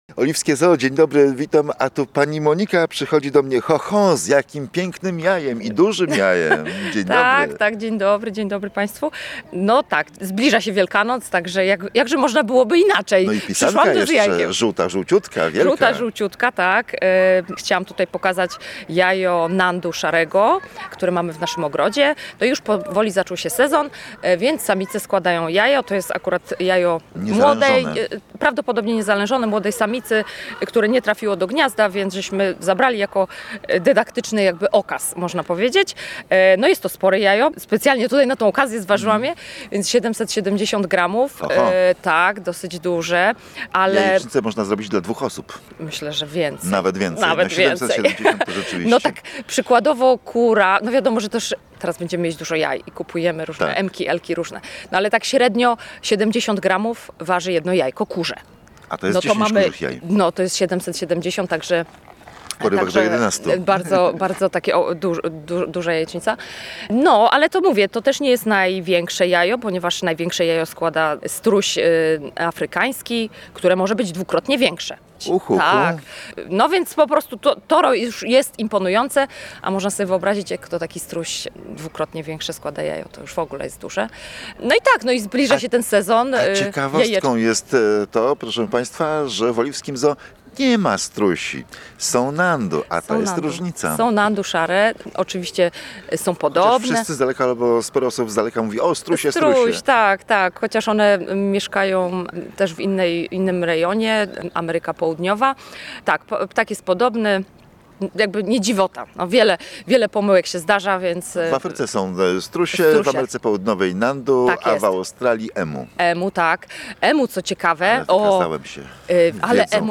Posłuchajcie rozmowy o nandu: https